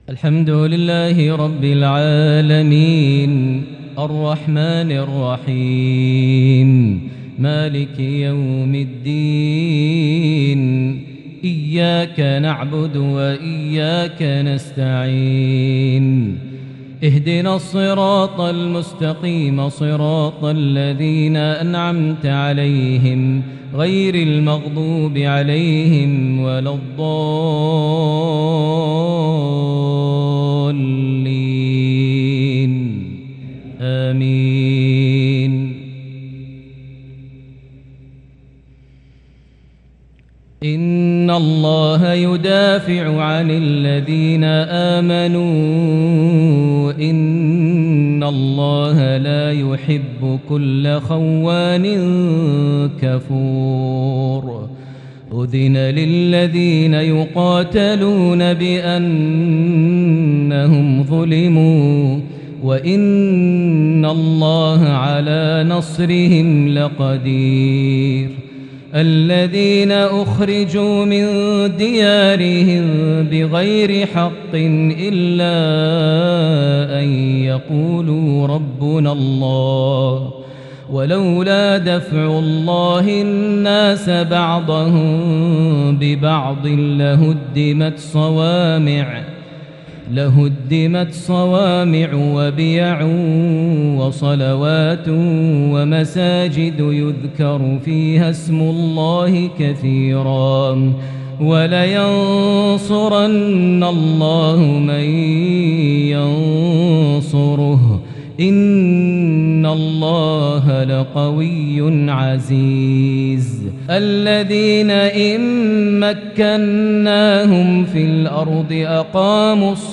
lsha 2-7-2022 prayer from Surah Al-Hajj 38-51 > 1443 H > Prayers - Maher Almuaiqly Recitations